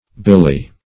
Billy \Bil"ly\, n.